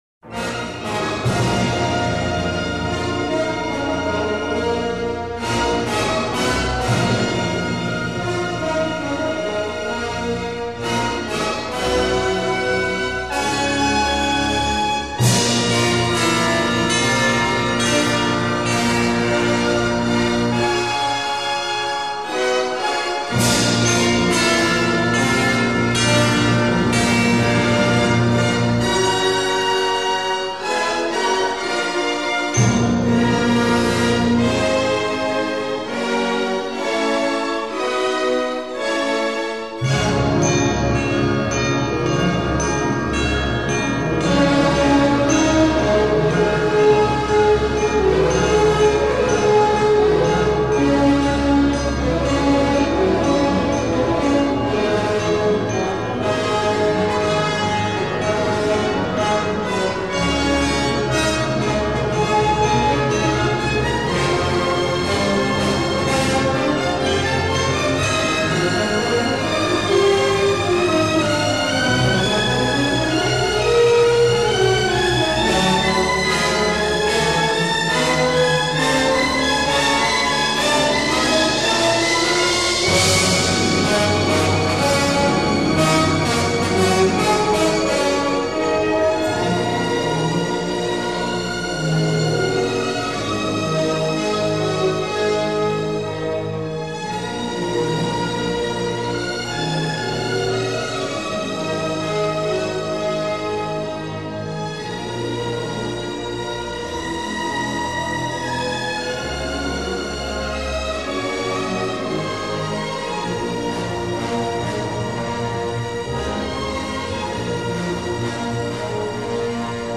电影原声
电影音乐采用多主题的交响音乐，其中竞技主题气势如虹、爱情主题炽烈而饱含伤痛。